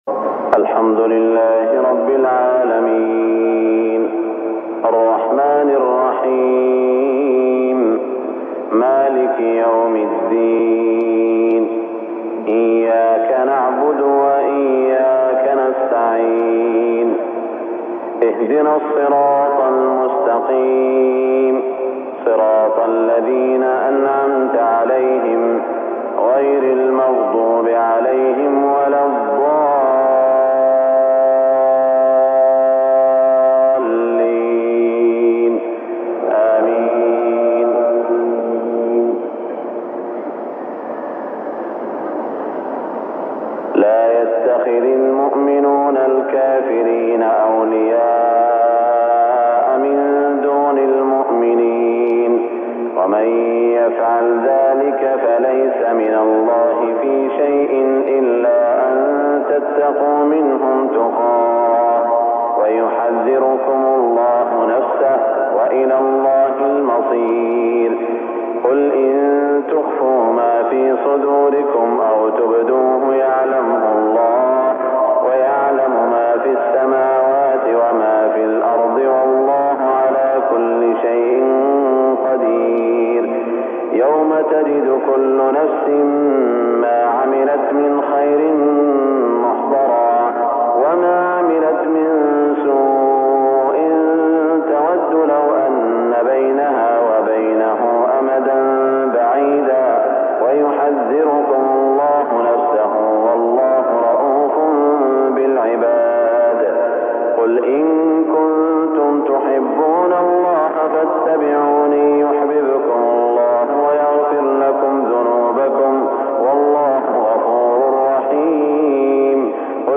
صلاة الفجر 1420 من سورة آل عمران > 1420 🕋 > الفروض - تلاوات الحرمين